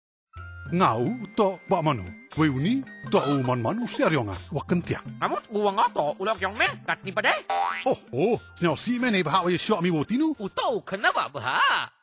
This is first in the series of five Radio PSA, address backyard farmers and their families. It uses a performer and a rooster puppet as a creative medium to alert families to poultry diseases and instill safe poultry behaviours.
Radio PSA